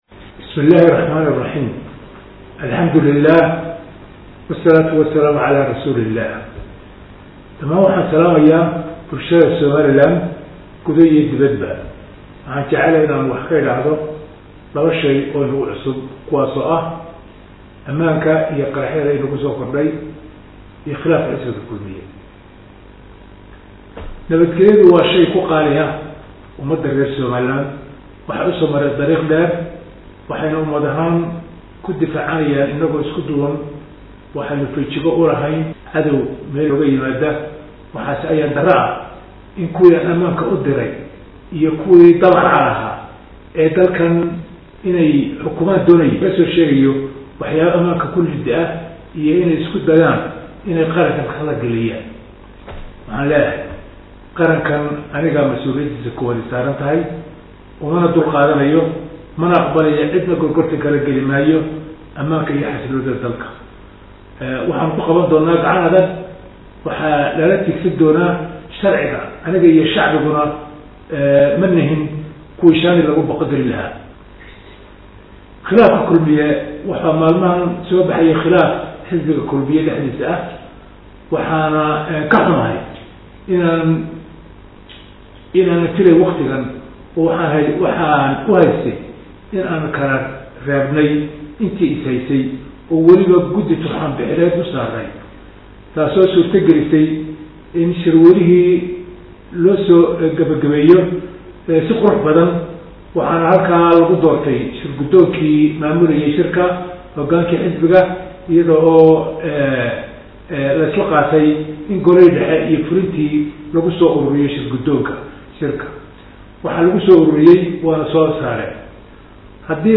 GUJI HALKAN OO MOBILKAAGA KA DHAGAYSO HADALKA MADAXWEYNAHA